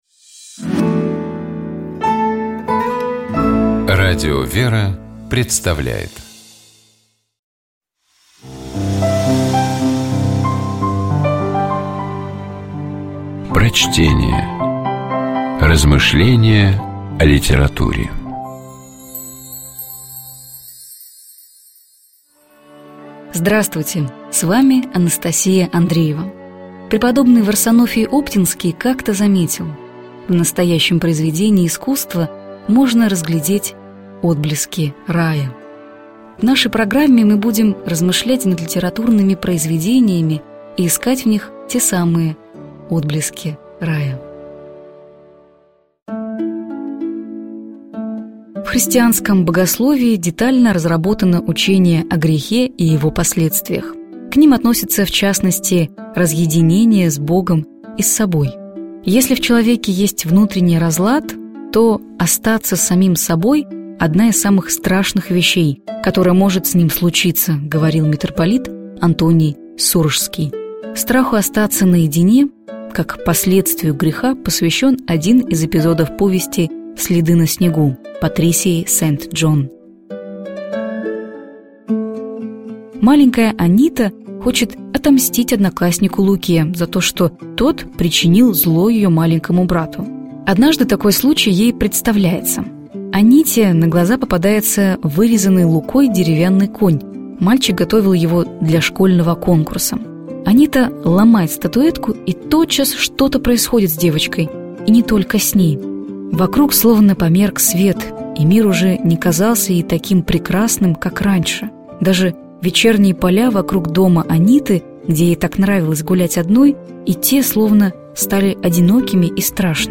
Prochtenie-P_-Sent-Dzhon-Sledy-na-snegu-O-strahe-ostatsja-naedine-s-soboj.mp3